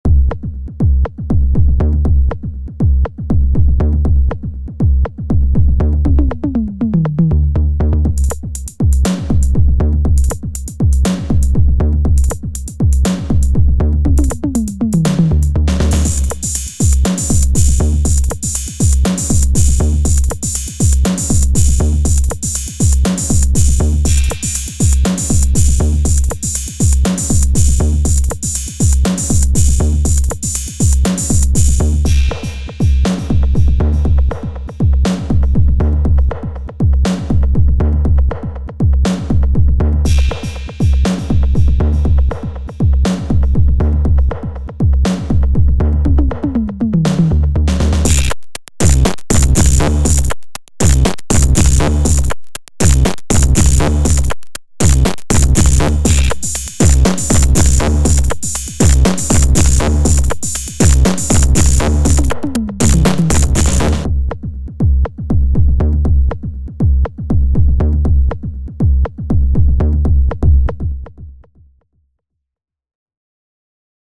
Just the analog part of Rytm in this short demo, no external processing either: